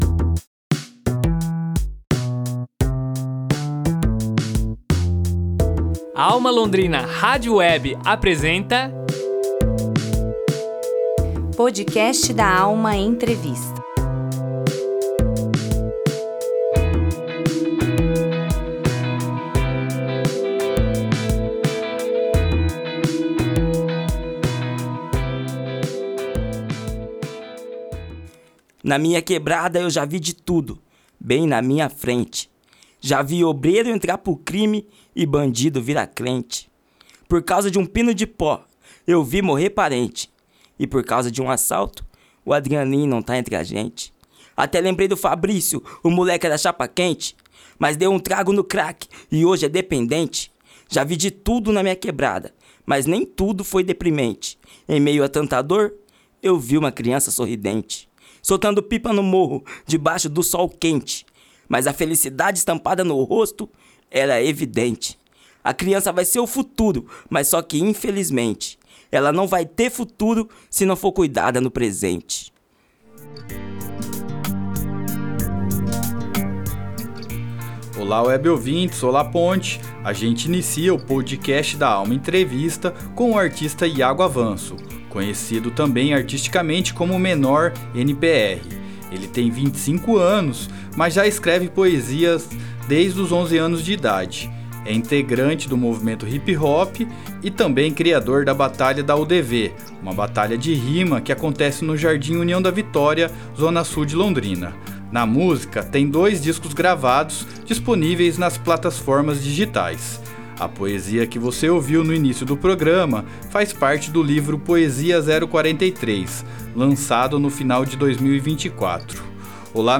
PODCAST DA ALMA ENTREVISTA EP 02